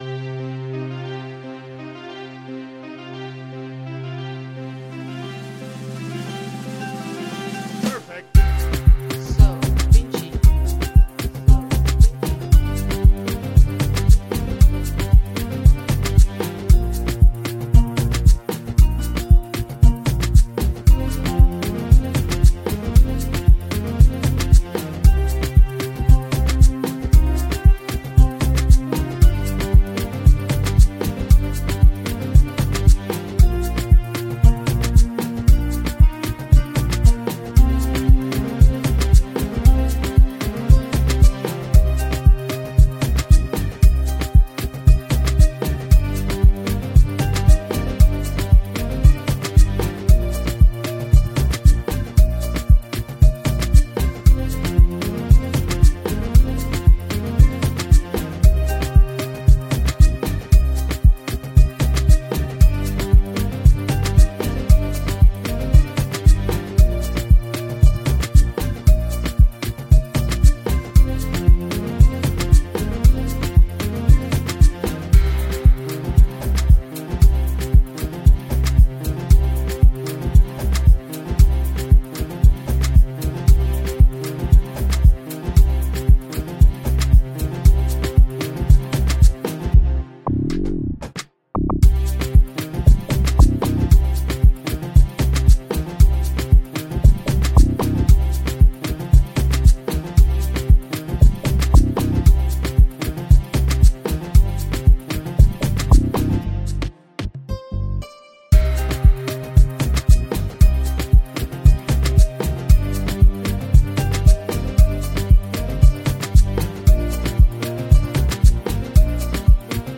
beat